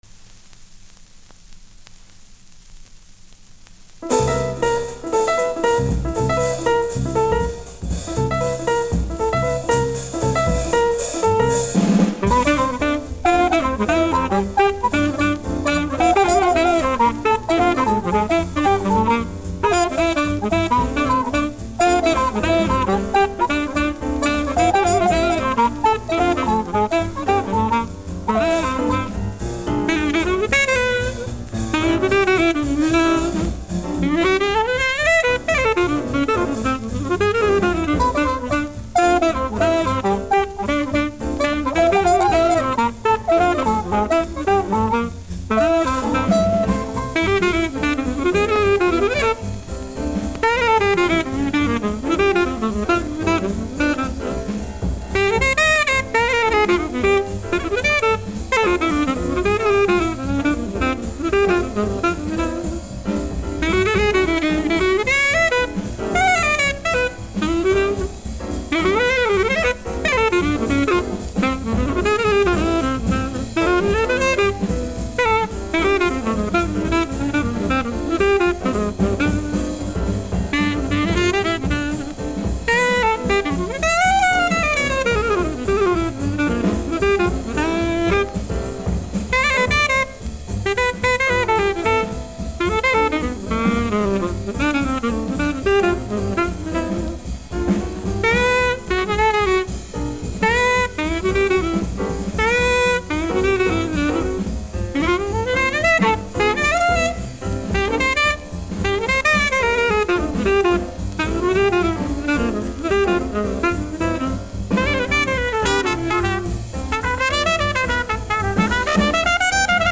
Original LO-FI take
LP моно ремастеринг